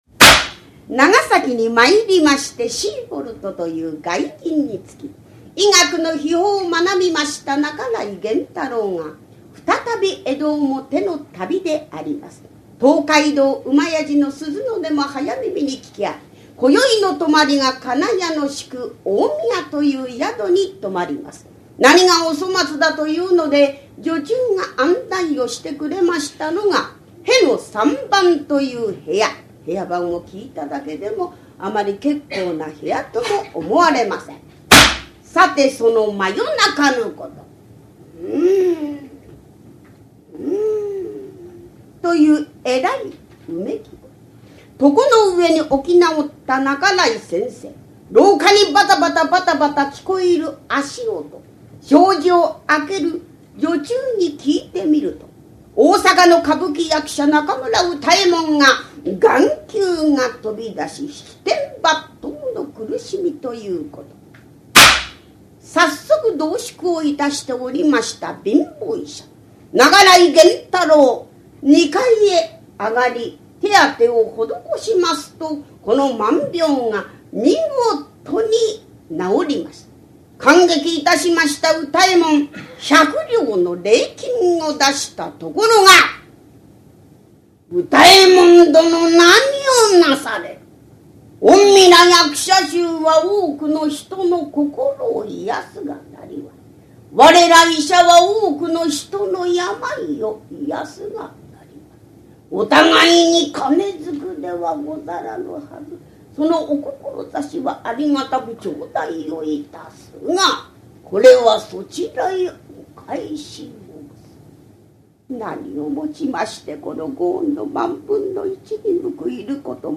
ハリセンで釈台を叩き「パパン」という音を響かせて調子良く語る、江戸時代から伝わる日本伝統の話芸「講談」。講談協会に所属する真打を中心とした生粋の講談師たちによる、由緒正しき寄席で行われた高座を録音した実況音源！
講釈師